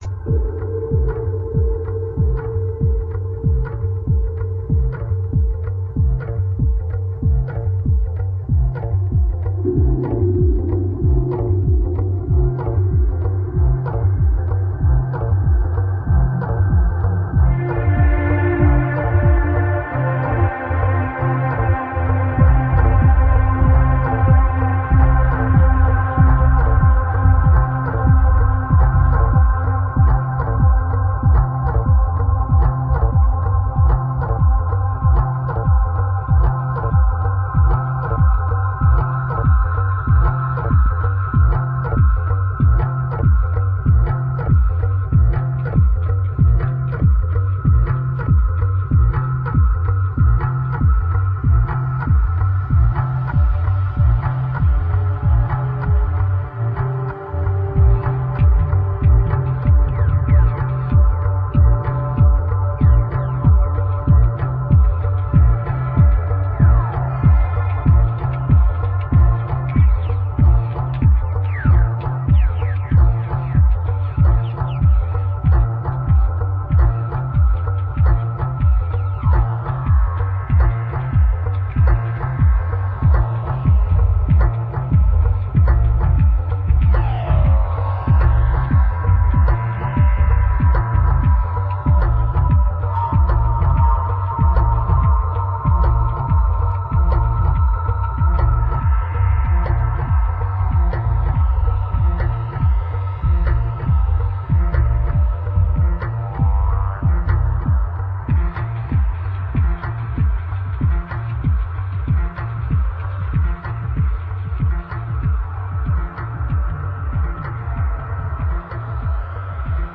loosely anchored in techno, ambient and bass music
stylistically open-ended
produced with an audiophile’s attention to detail
Electronix Bass Drum and Bass